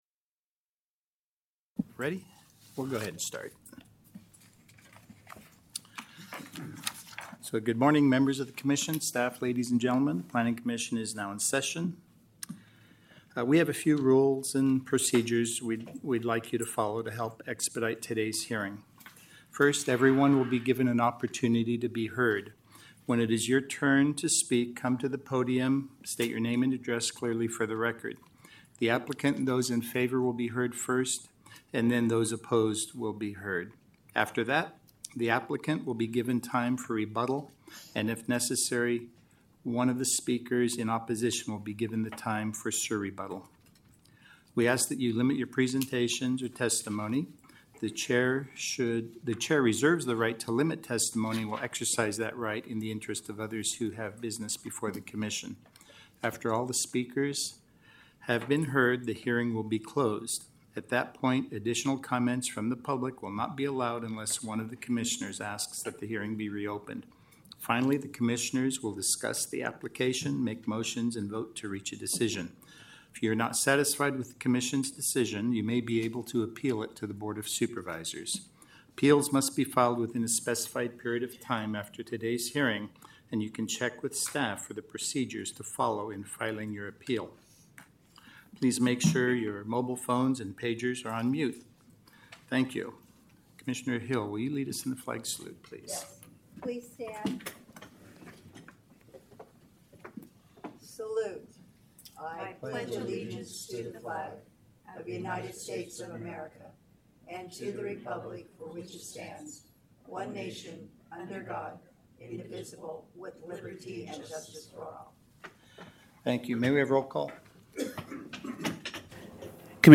April 10, 2025 Fresno County Planning Commission Hearing